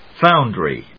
音節found・ry 発音記号・読み方
/fάʊndri(米国英語), ˈfaʊndri:(英国英語)/